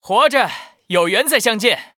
文件 文件历史 文件用途 全域文件用途 Timothy_fw_01.ogg （Ogg Vorbis声音文件，长度2.0秒，108 kbps，文件大小：26 KB） 文件说明 源地址:游戏解包语音 文件历史 点击某个日期/时间查看对应时刻的文件。 日期/时间 缩略图 大小 用户 备注 当前 2019年1月24日 (四) 04:35 2.0秒 （26 KB） 地下城与勇士  （ 留言 | 贡献 ） 分类:蒂莫西(地下城与勇士) 分类:地下城与勇士 源地址:游戏解包语音 您不可以覆盖此文件。